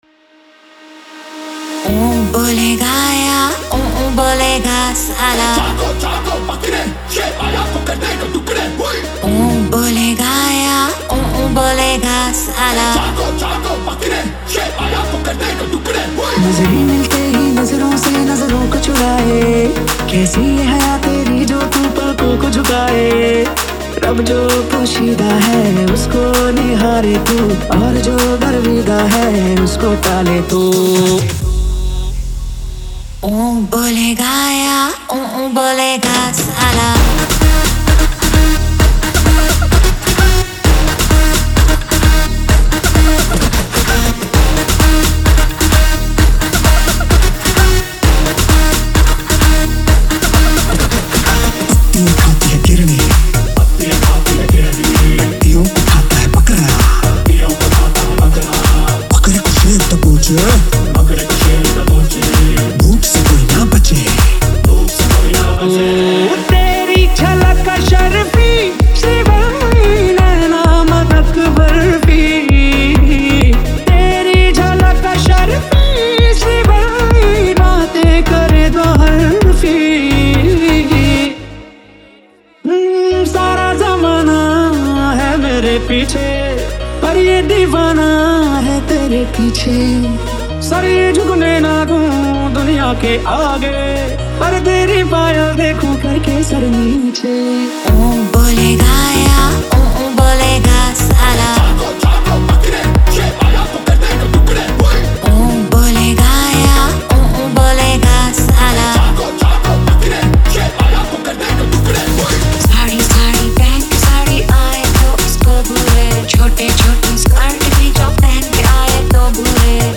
Single DJ Remix Songs